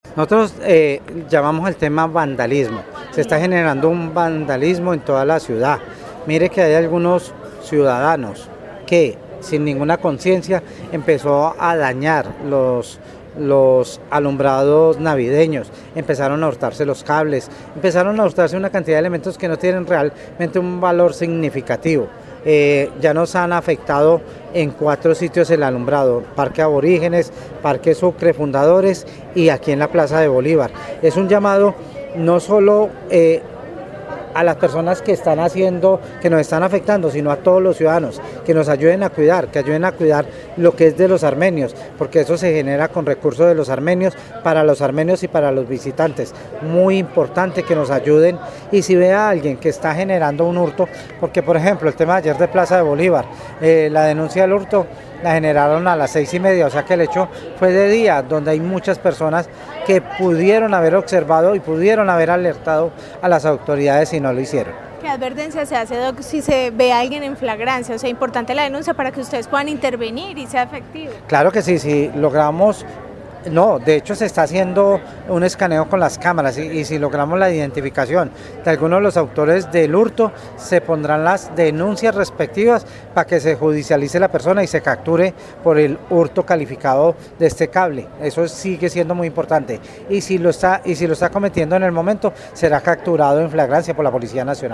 Secretario de Gobierno de Armenia